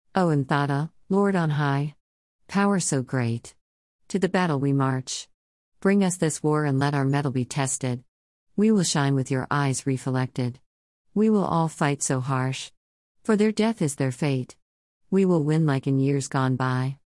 Paragrim-Battle-March-1.mp3